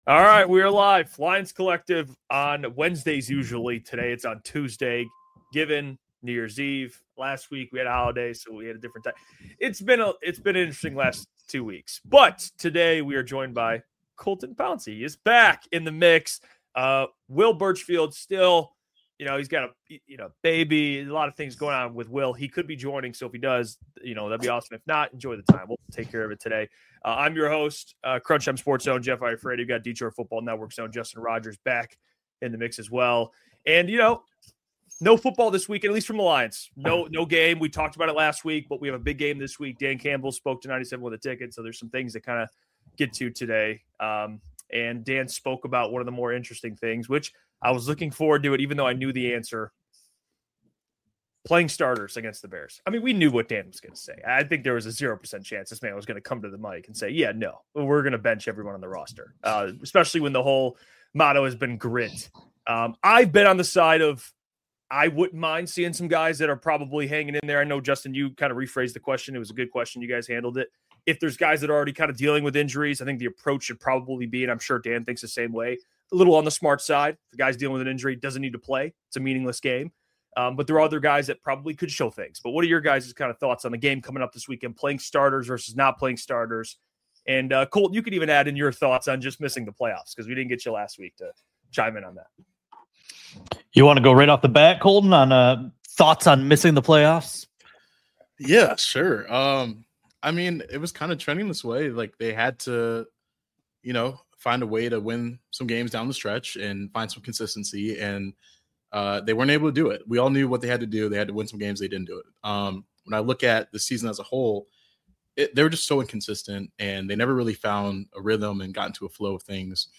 Make sure to subscribe to Lions Collective for new roundtable episodes every week, live, on Wednesday at 6 pm ET You can also hear every episode of Lions Collective wherever you get your podcasts!